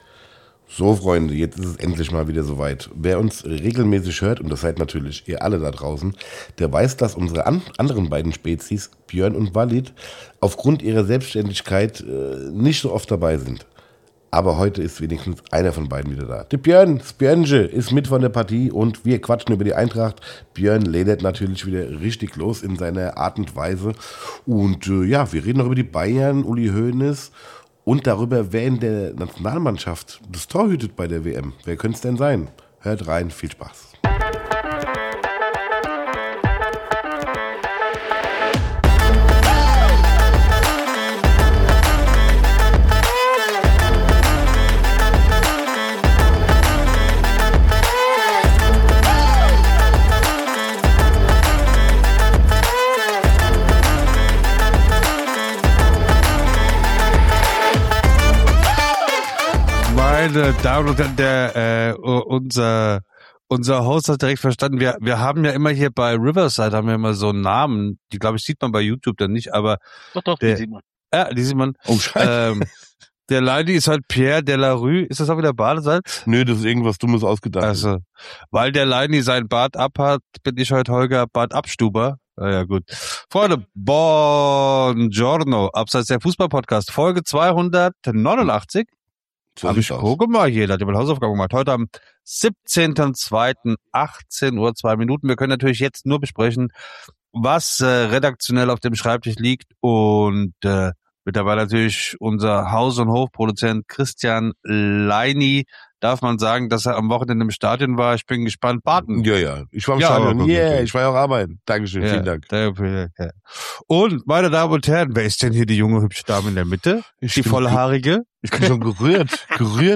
Wir sind wieder zu dritt - zumindest in dieser Folge.